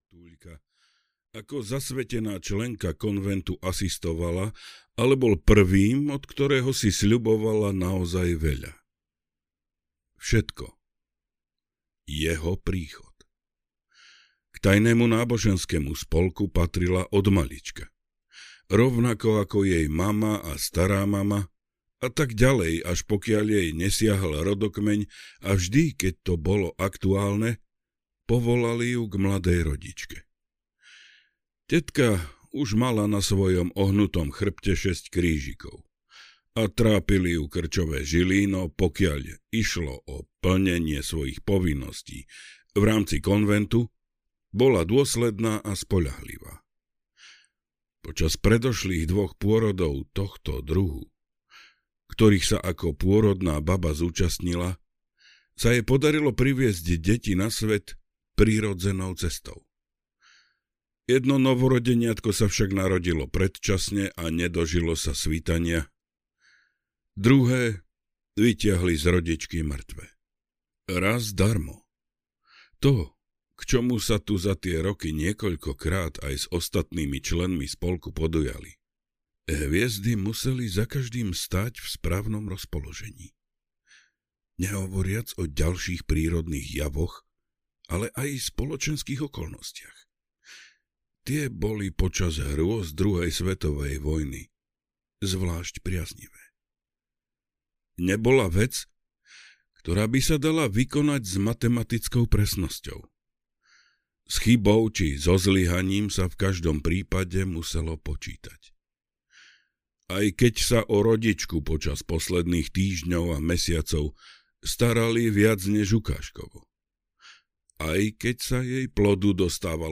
Dom 490 audiokniha
Ukázka z knihy